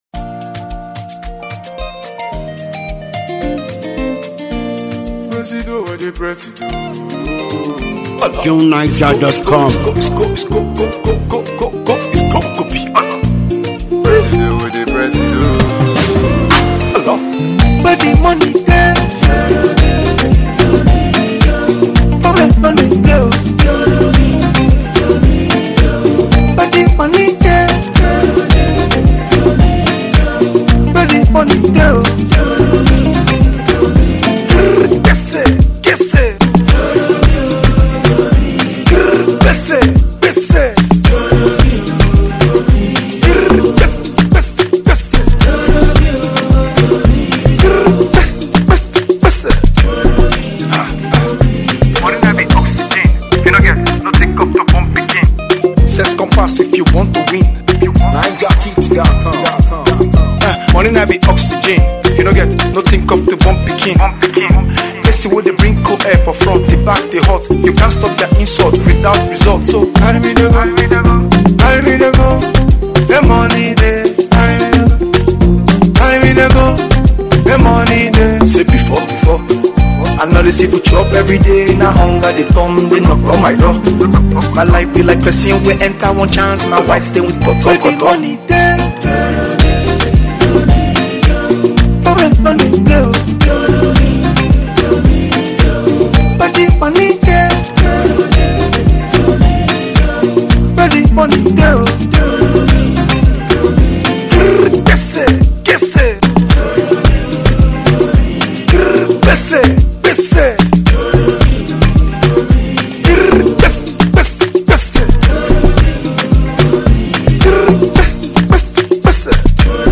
stunning and emotionally stirring song
” to your library of mellow music.